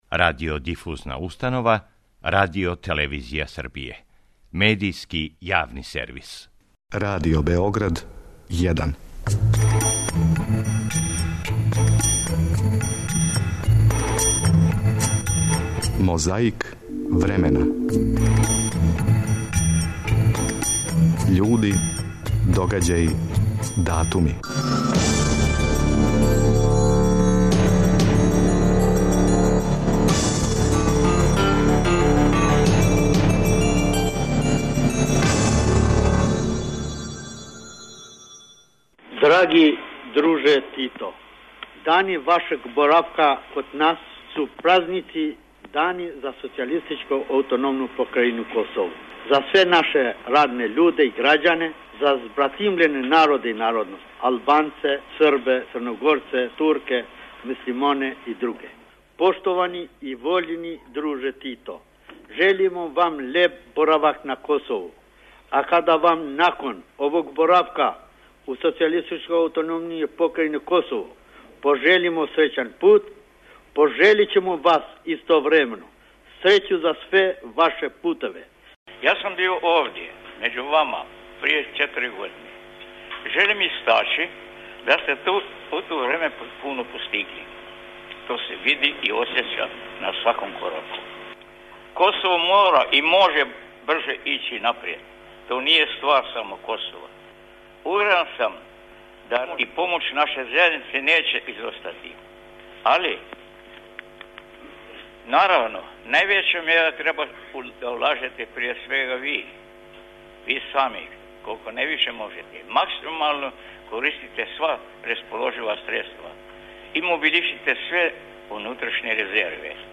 У оквиру званичне посете највећег сина наших народа и народности Косову и Метохији, 17. октобра 1979. године, на свечаној вечери у приштинском хотелу Гранд здравице су разменили друг председник Јосип Броз и друг Џавид Нимани.
Подсећа на прошлост (културну, историјску, политичку, спортску и сваку другу) уз помоћ материјала из Тонског архива, Документације и библиотеке Радио Београда. Свака коцкица Мозаика је један датум из прошлости.